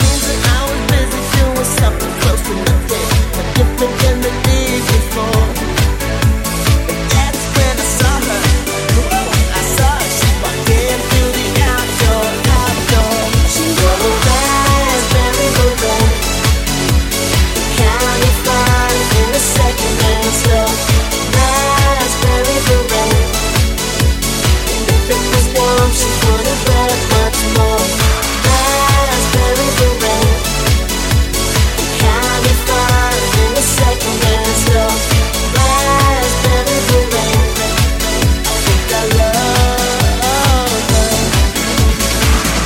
hits remixed
Genere: club, dance, edm, electro, house, successi, remix